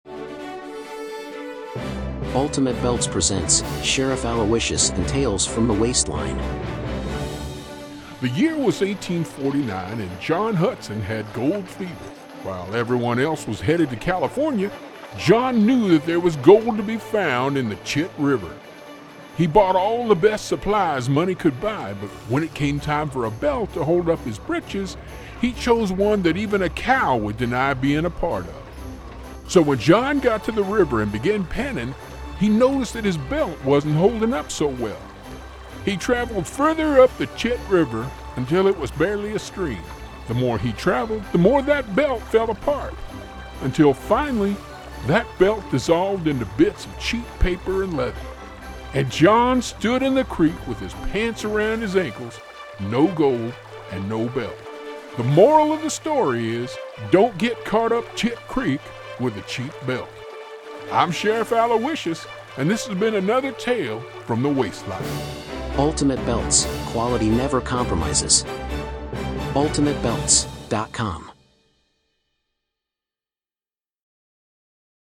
Ultimate Belt's Commercials